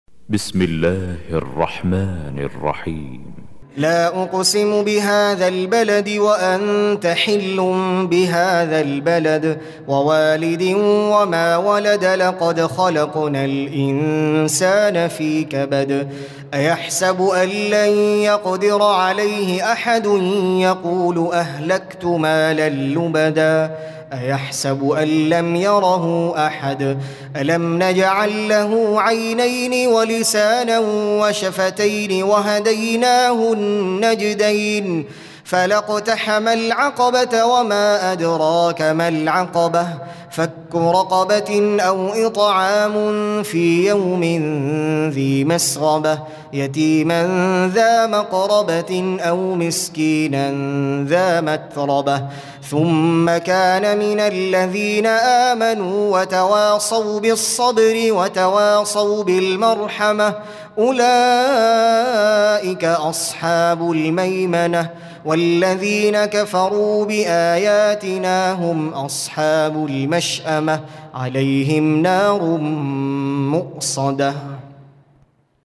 Surah Al-Balad سورة البلد Audio Quran Tarteel Recitation
Surah Sequence تتابع السورة Download Surah حمّل السورة Reciting Murattalah Audio for 90.